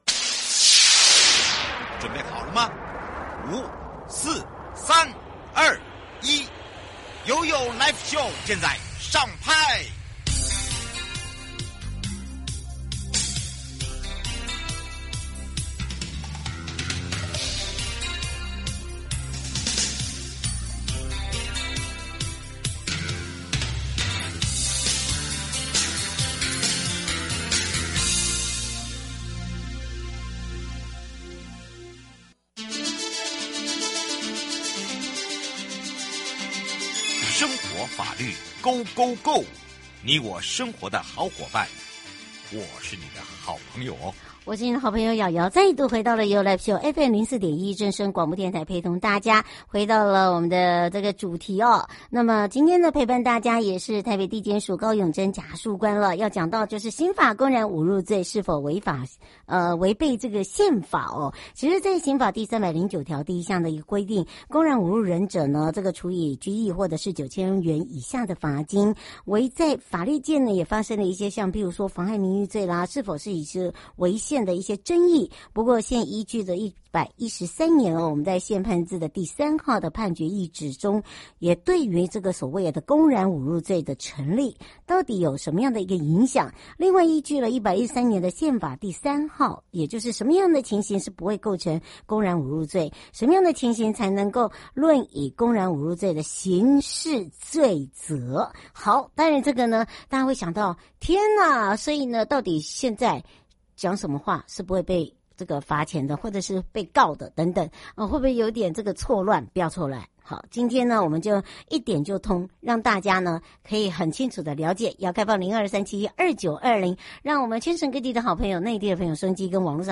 重播